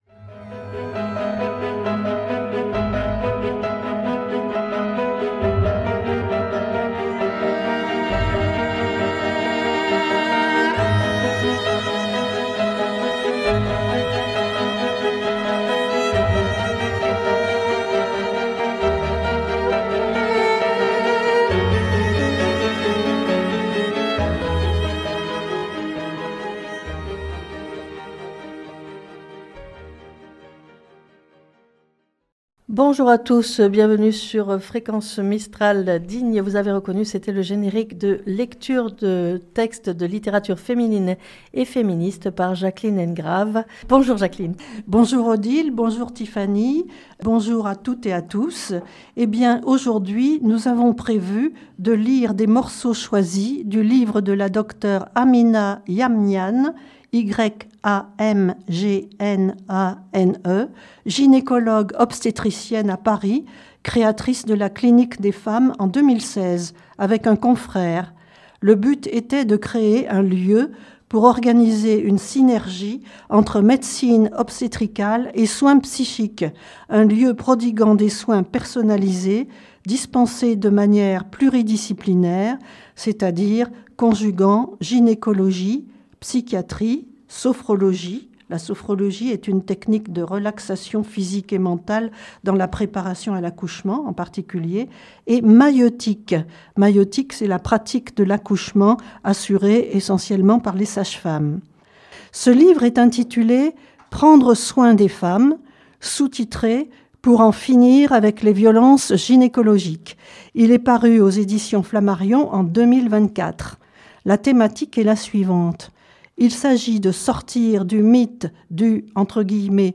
Lecture de textes de littérature féminine et féministe N°14